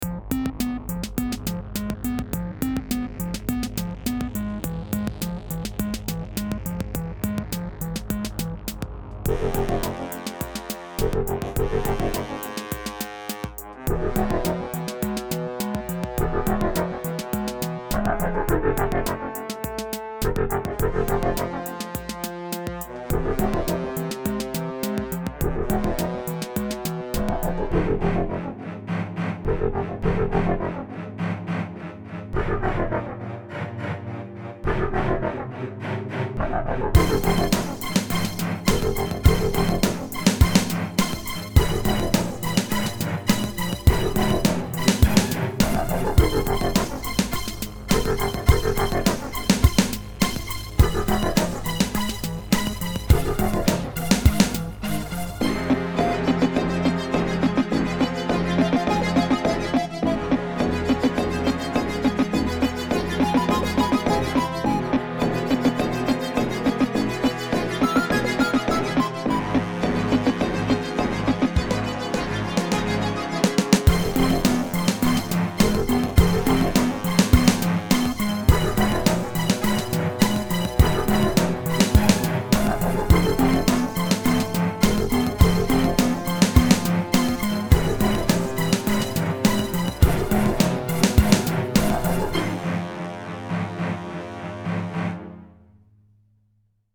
Filed under: Instrumental